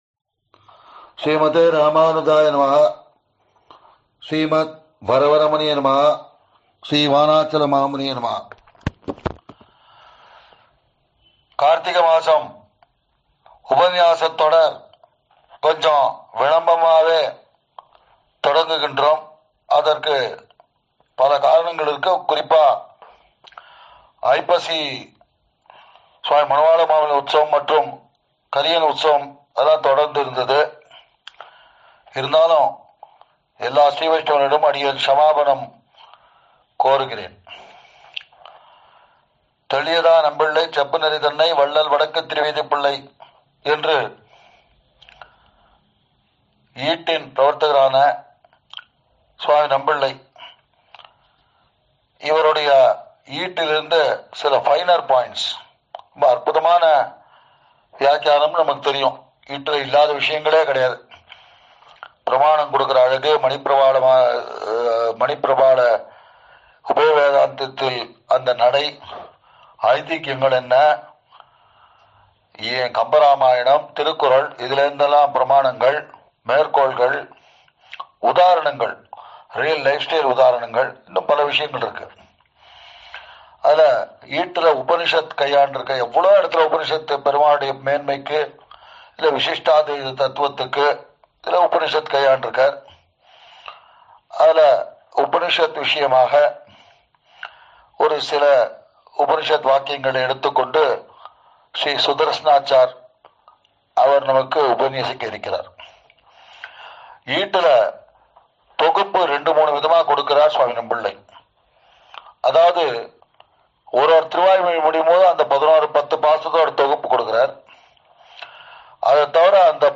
2023-Kaarththigai-Upanyasangal-Introduction – Under Topic : EETTIN NUNSUVAI